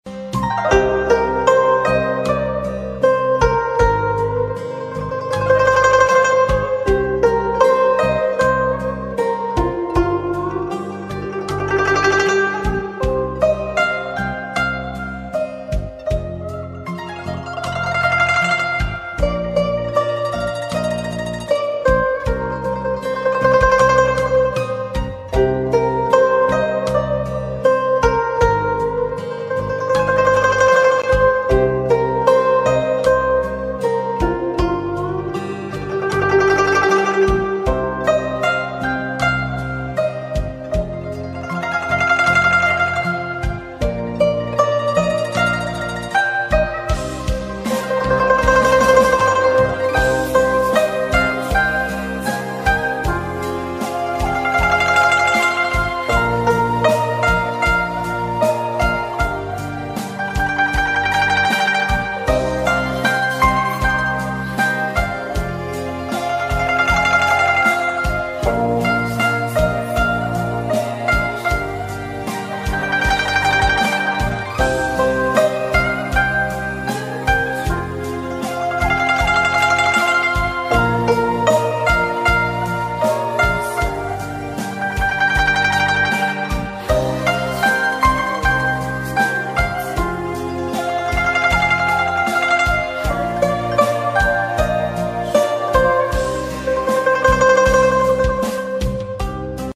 cực phẩm cổ phong, âm thanh trong trẻo và sâu lắng